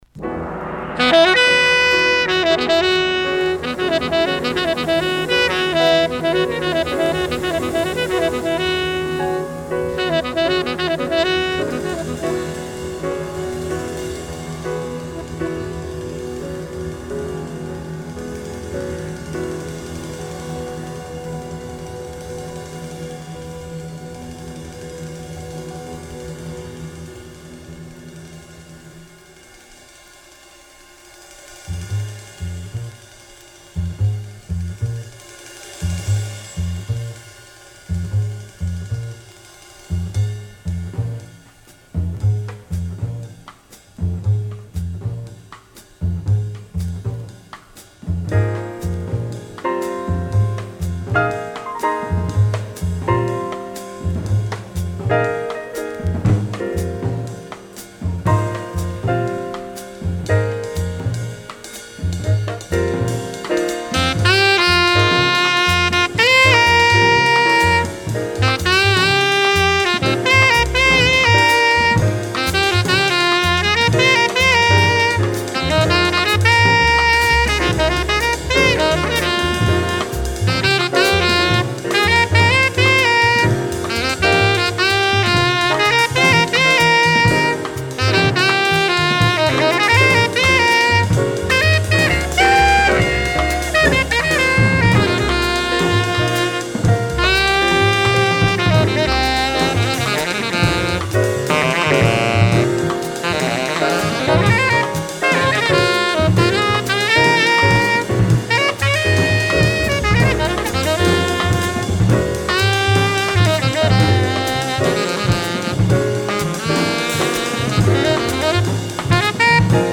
Second mono pressing ca. 1965